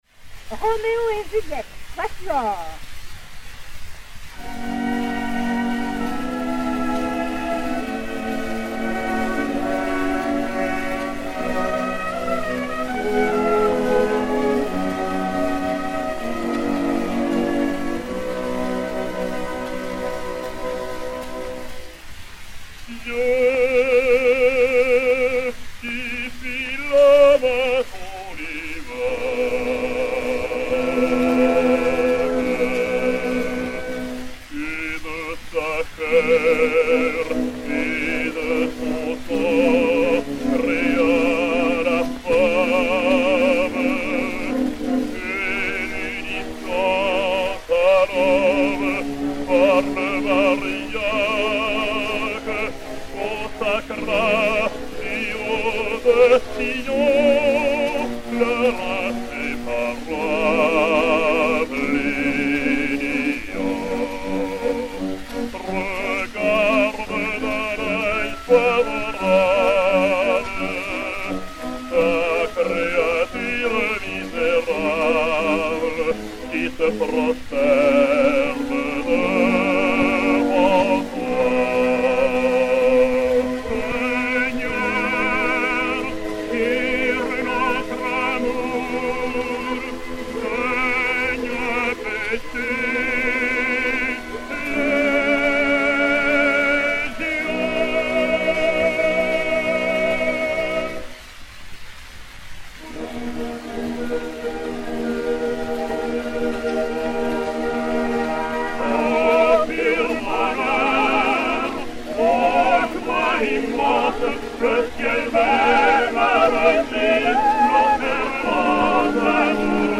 Quatuor
et Orchestre